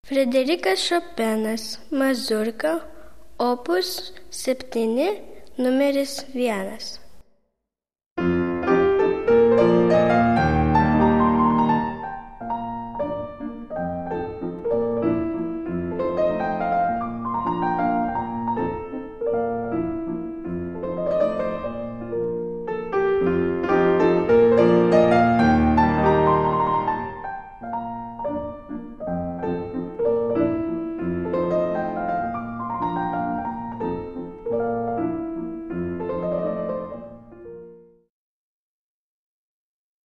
Kirpt - III.8 Mazurka.mp3